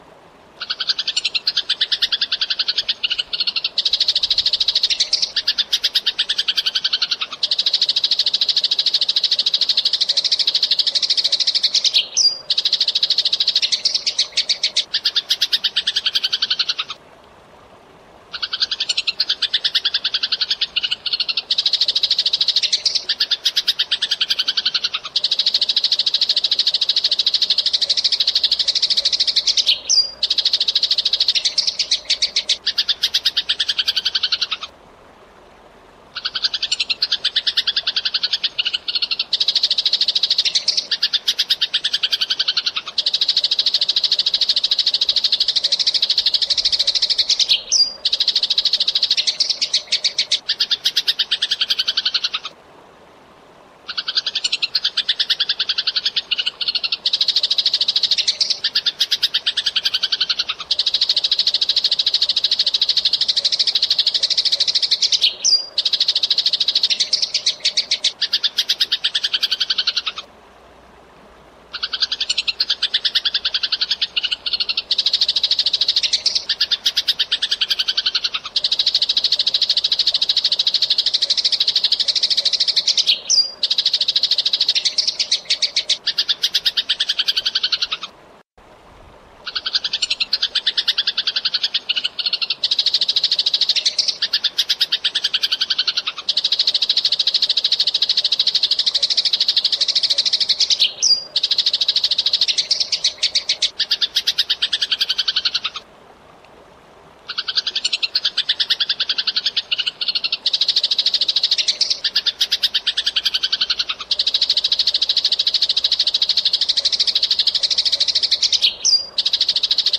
Suara Burung Gereja Tarung Besetan Kasar
Kategori: Suara burung
Masteran jitu untuk burung gereja gacor dengan speed rapet dan ngeroll abis. Dapatkan suara jernih, durasi panjang, dan nyeret panjang yang cocok untuk melatih burung agar tampil maksimal di setiap tarung.
suara-burung-gereja-tarung-besetan-kasar-id-www_tiengdong_com.mp3